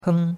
heng1.mp3